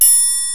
Index of /m8-backup/M8/Samples/Fairlight CMI/IIX/PERCUSN1
TRIANGLE.WAV